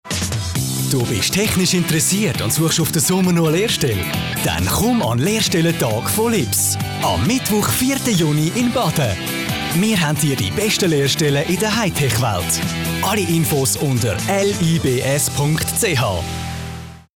Werbung Schweizerdeutsch (AG)
Schauspieler mit breitem Einsatzspektrum.